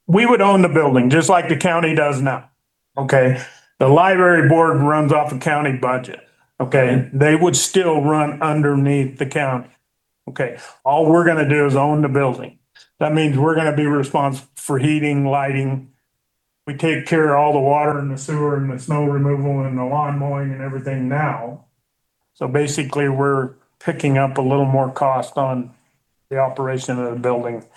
Prompted by a question from a meeting attendee, Mayor Davis explained how the deal will work. The county-funded library system will continue to manage daily operations, while Saratoga will own the building and cover utility costs.